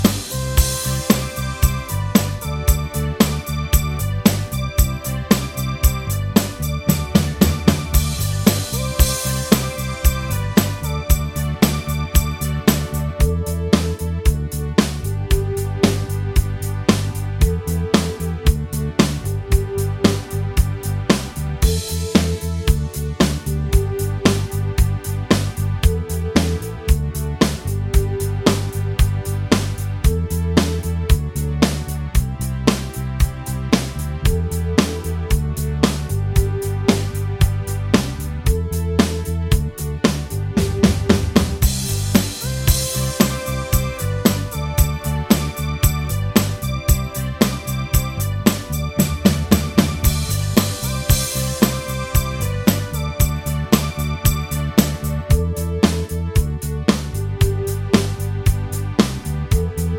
Minus Main Guitars For Guitarists 3:03 Buy £1.50